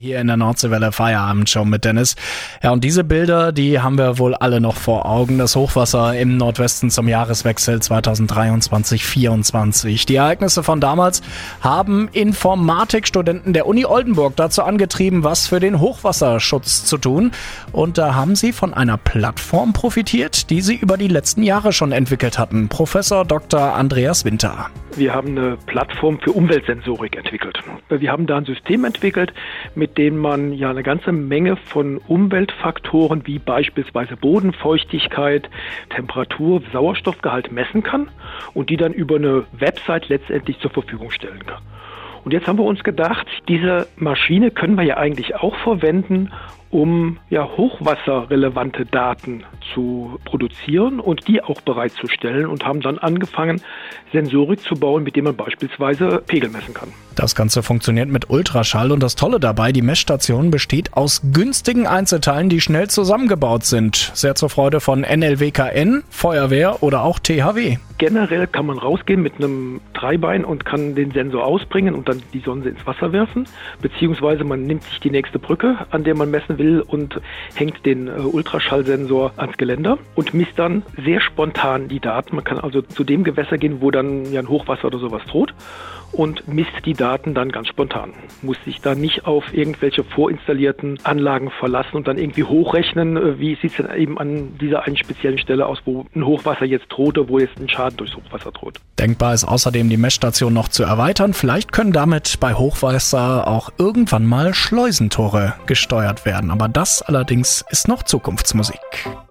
Radio-Interview
Radiointerview